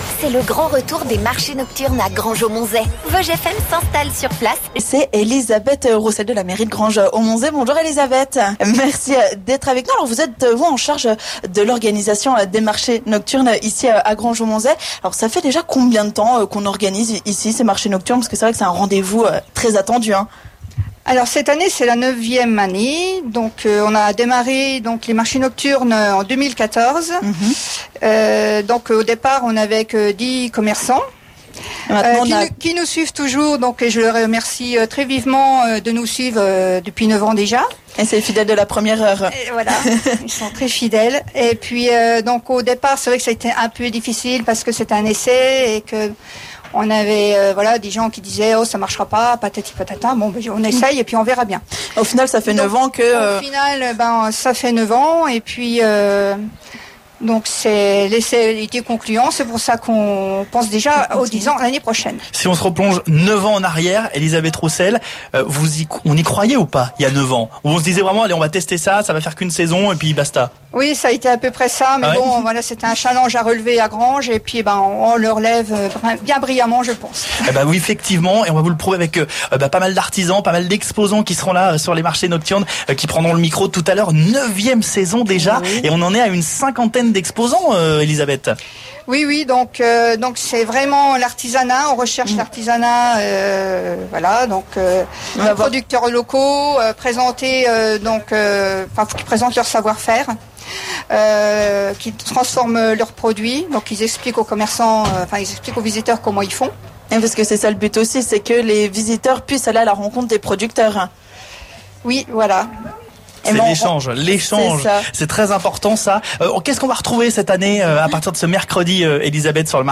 Revivez le live du marché nocturne de Granges!